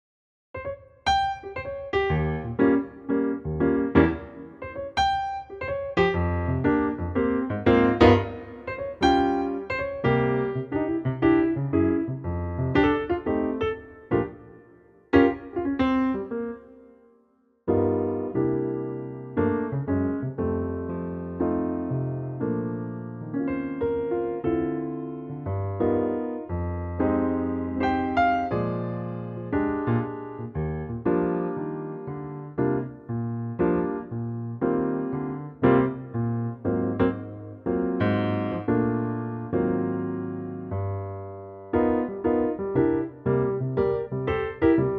Unique Backing Tracks
key - Bb - vocal range - F to G
Piano only arrangement